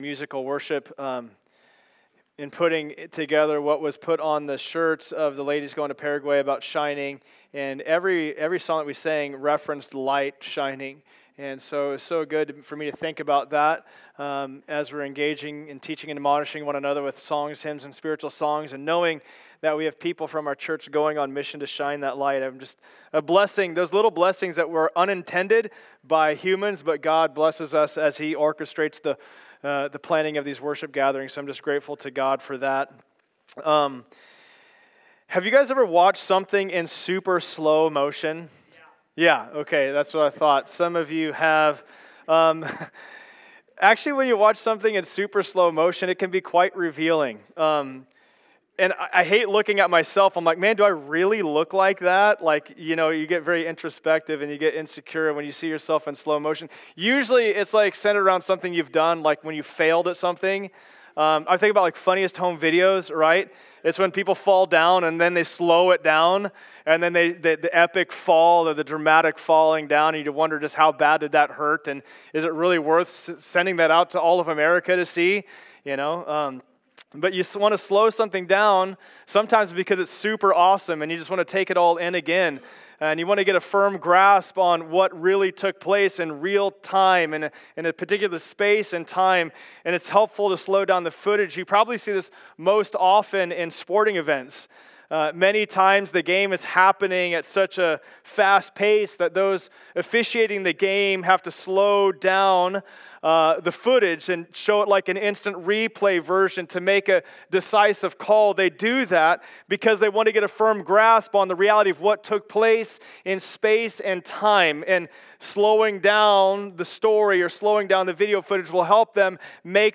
Passage: Mark 8:22-30 Service Type: Sunday Service Download Files Notes « Do You Not Yet Understand?